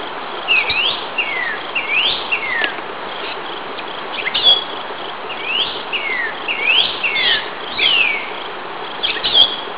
Northern Cardinal
Roselle, NJ, 6/20/99, "whirr-a-chee" (77kb)